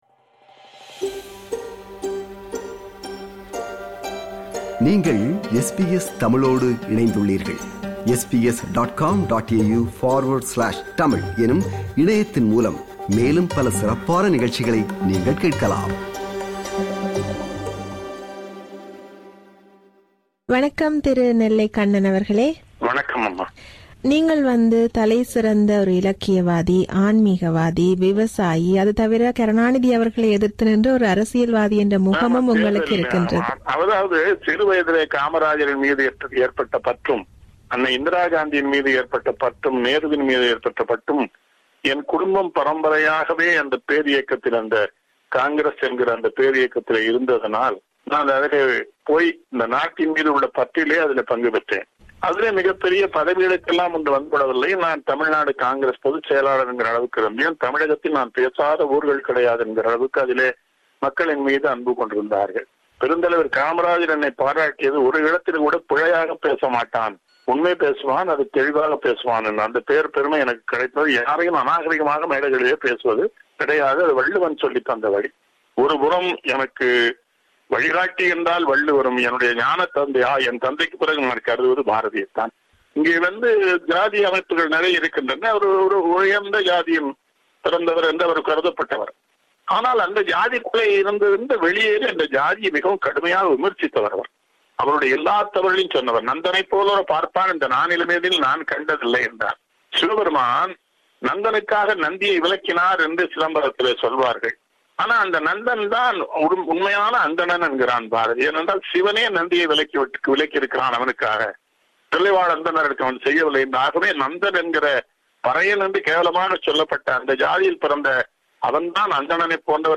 கடந்த 2016ம் ஆண்டு சிட்னி தமிழ்க் கலை மற்றும் பண்பாட்டுக் கழகம் நடத்திய நிகழ்வொன்றில் கலந்துகொள்வதற்காக ஆஸ்திரேலியா வருகை தந்தநெல்லைக் கண்ணன் அவர்கள், SBS தமிழுக்கு நீண்ட நேர்காணல் ஒன்றை வழங்கியிருந்தார். அதன் சுருக்கத்தை இப்போது மறு ஒலிபரப்புச் செய்கிறோம்.